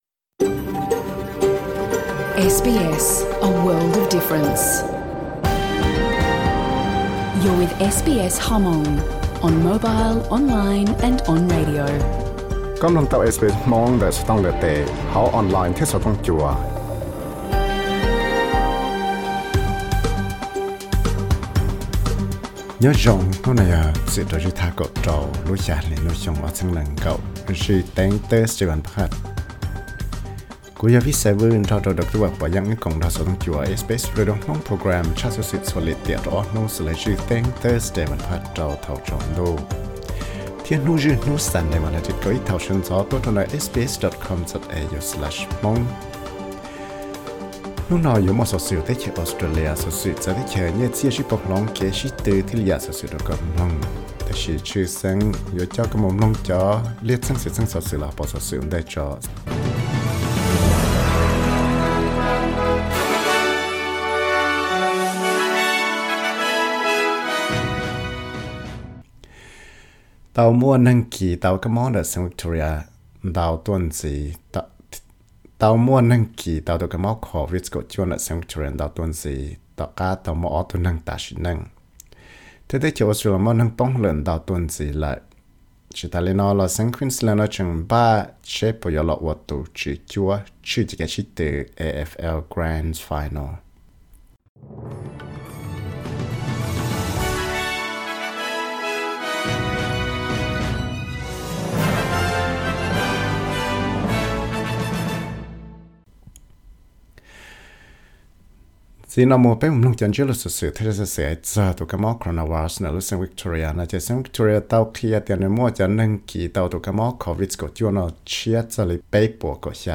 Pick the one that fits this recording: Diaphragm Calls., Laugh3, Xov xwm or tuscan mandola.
Xov xwm